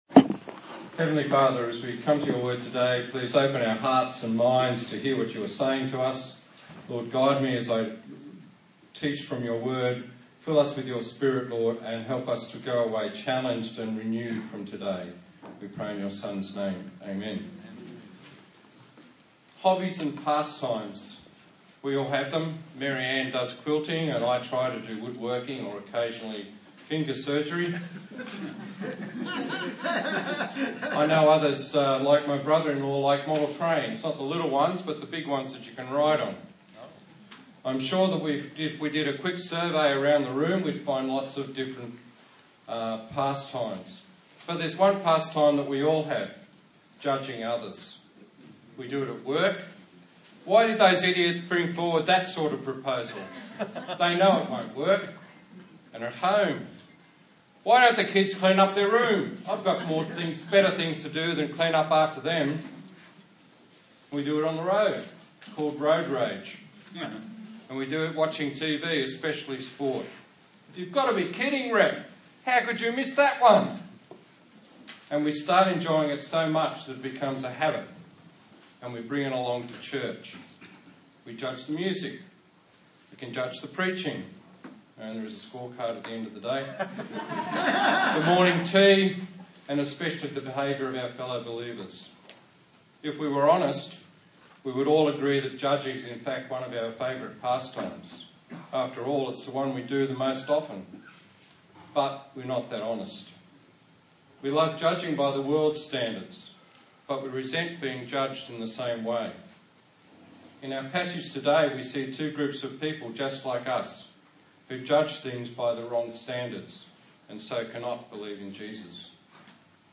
12/06/2016 Right Judgment Preacher
Service Type: Sunday Morning